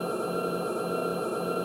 SFX_Krahn_Loop.wav